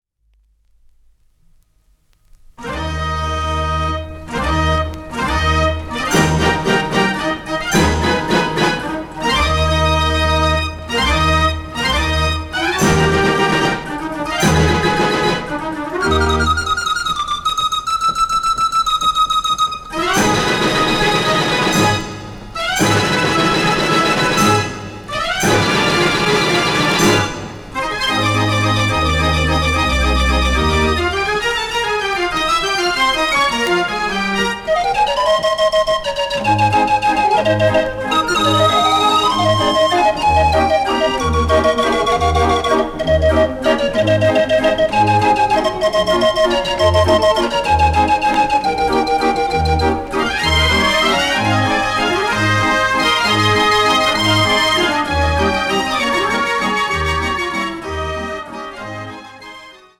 Formaat LP